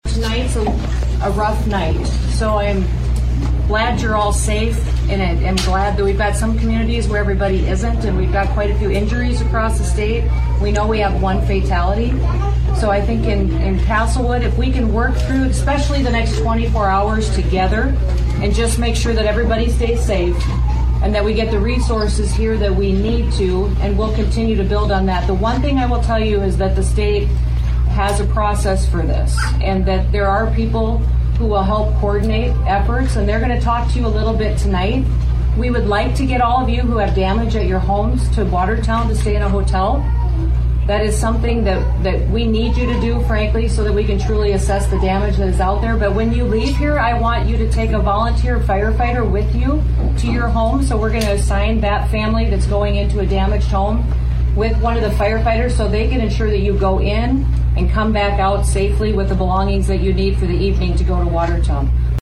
Governor Kristi Noem visited Castlewood last night. Here’s what she told local residents…